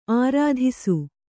♪ ārādhisu